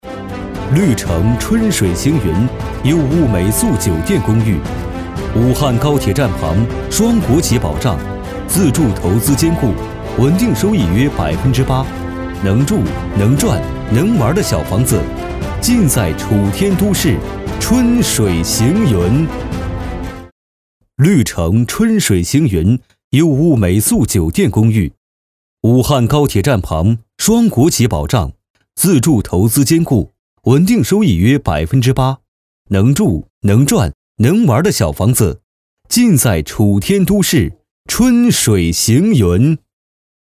男10号